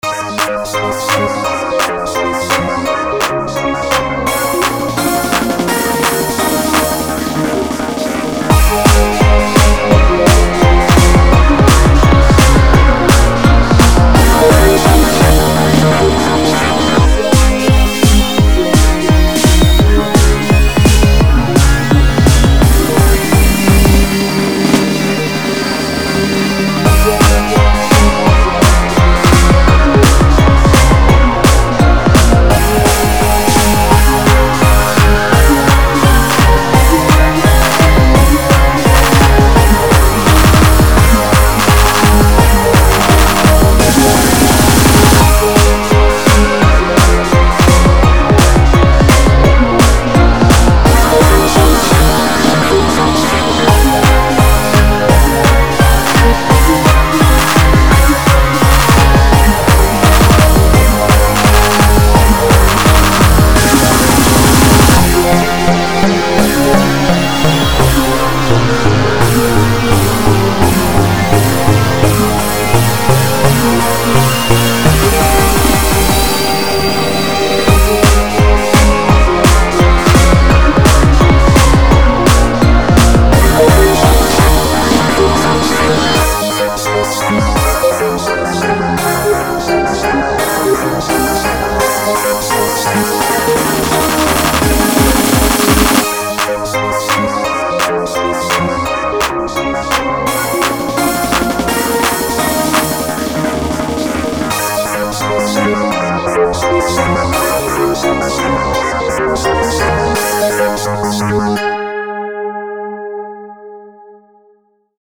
An instrumental recording.